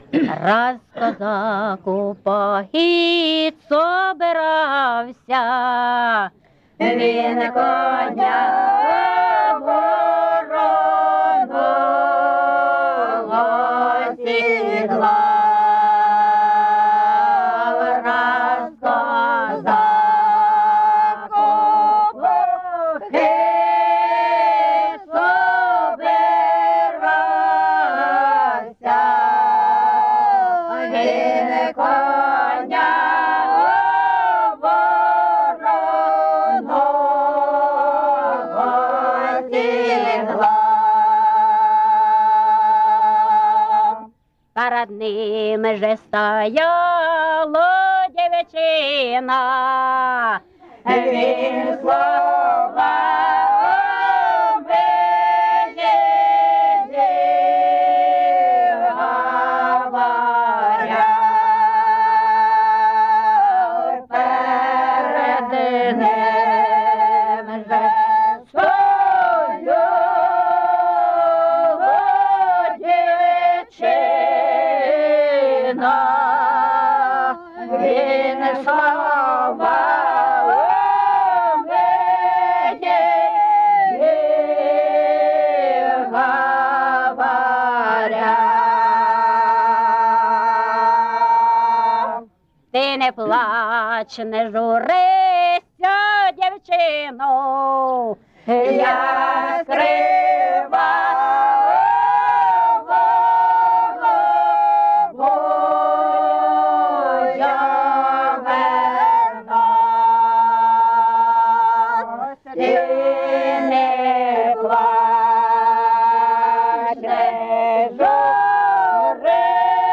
ЖанрПісні з особистого та родинного життя, Козацькі
Місце записус. Шарівка, Валківський район, Харківська обл., Україна, Слобожанщина